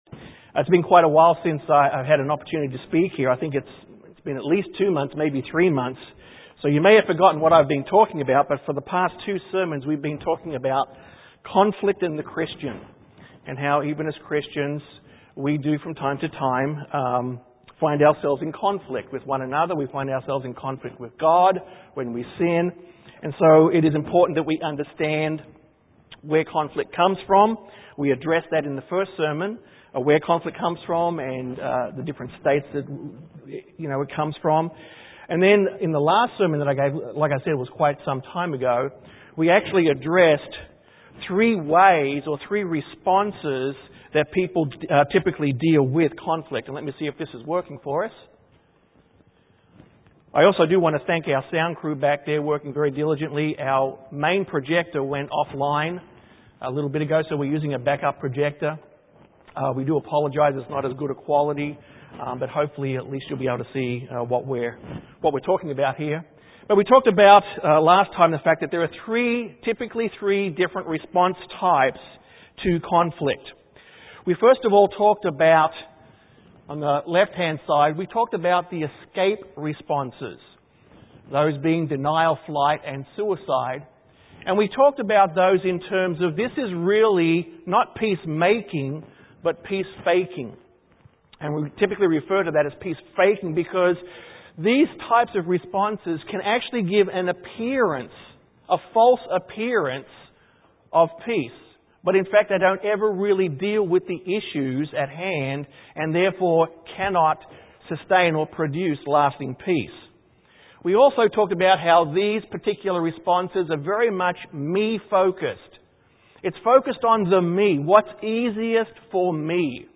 This sermon is part 3 in a 3 part series dealing with conflict. Conflict, whether minor or major is a part of the human experience and as Christians it is important that we know how, and how not to deal with it. This sermon focused on the biblical responses to conflict.